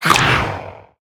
assets / minecraft / sounds / mob / phantom / bite1.ogg
bite1.ogg